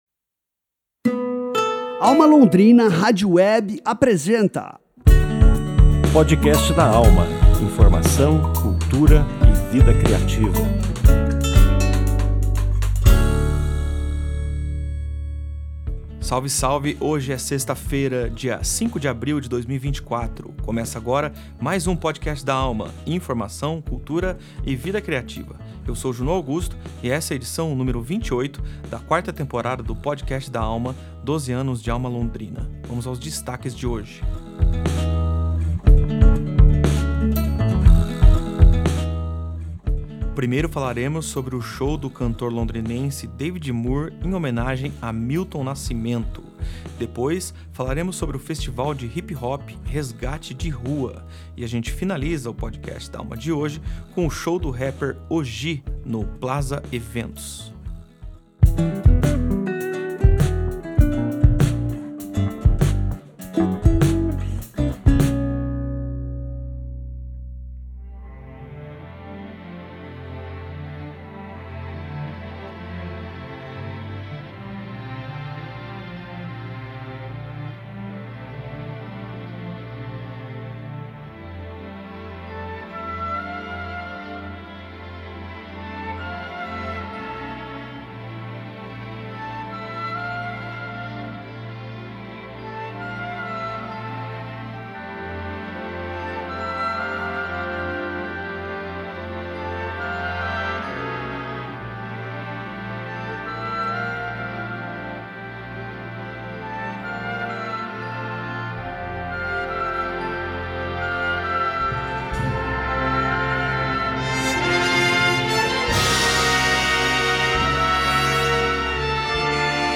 Em conversa com a AlmA Londrina Rádio Web